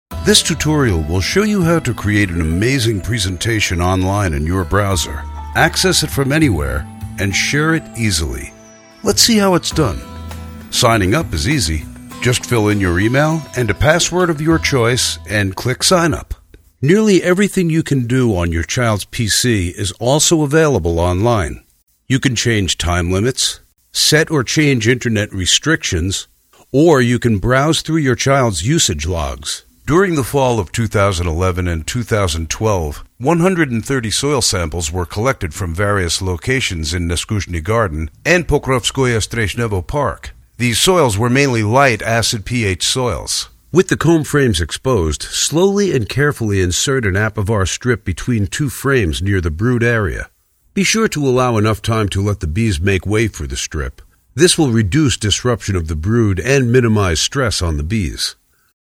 mid-atlantic
Sprechprobe: eLearning (Muttersprache):
Naturally deep, rich, smoky and soothing, but extremely diverse!